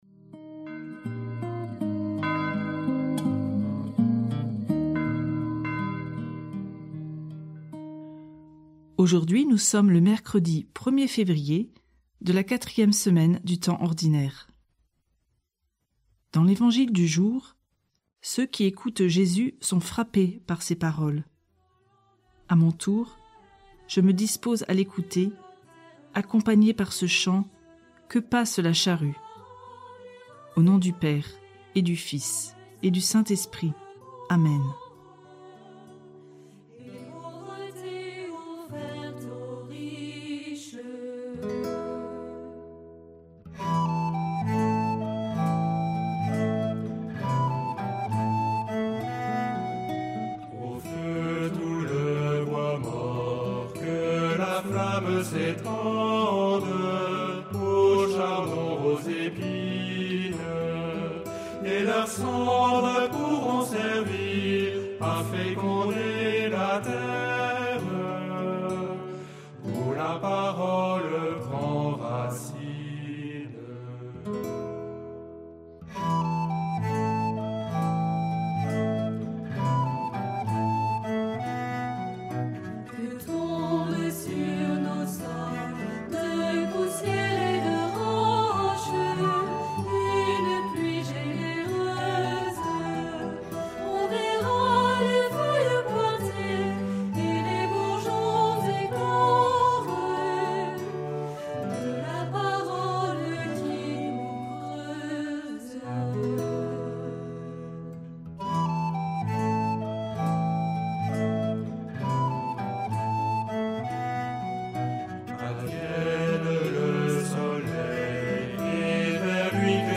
Prière audio avec l'évangile du jour - Prie en Chemin
Musiques